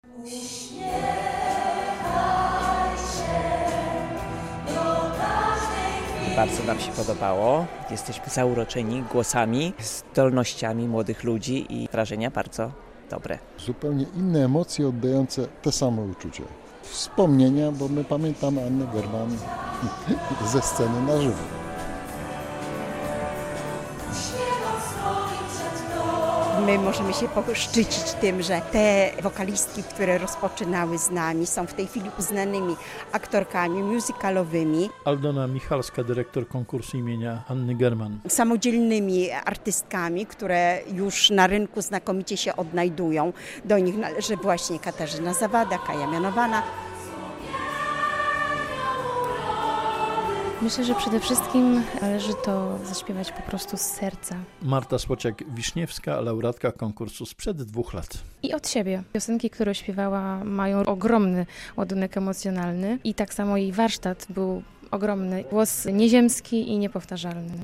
21 solistów, laureatów dziesięciu edycji Międzynarodowego Festiwalu Piosenki - Anna German, wystąpiło na koncercie jubileuszowym tegorocznego X już wydania imprezy.